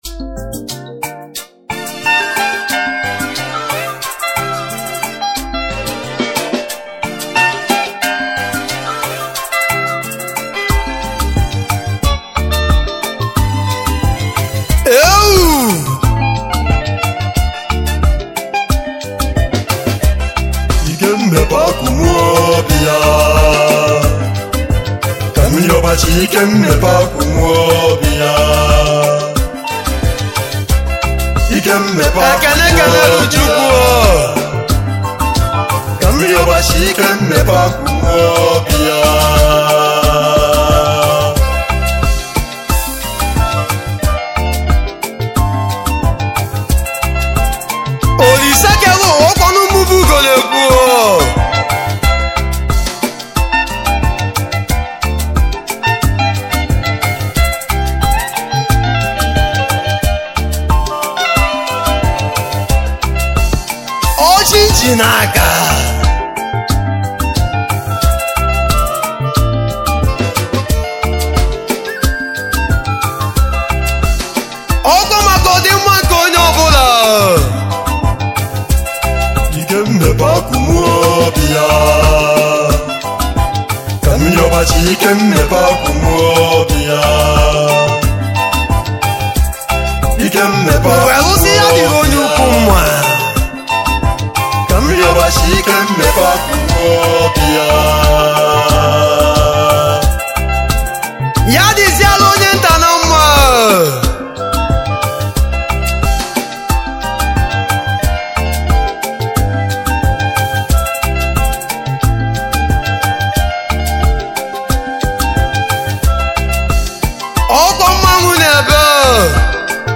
highlife music band